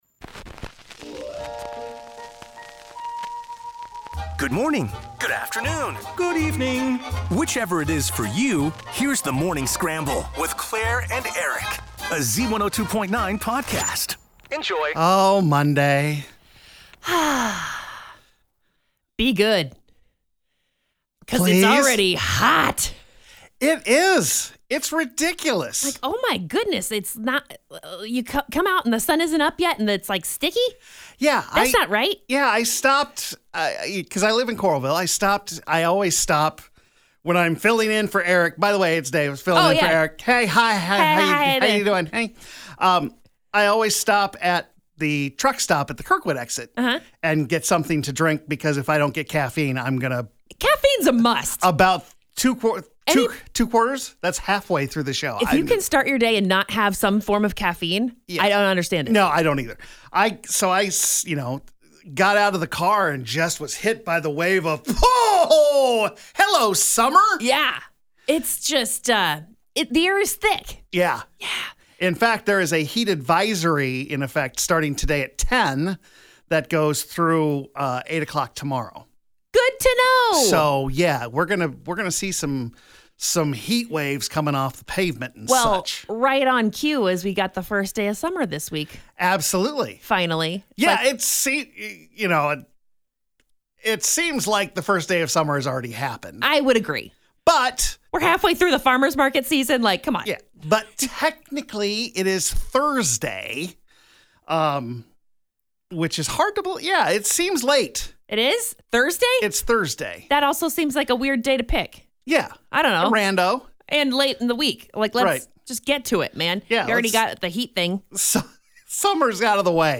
He was out amongst the people at the Cedar Rapids Downtown Farmers Market; talking rhubarb and chatting up the single ladies.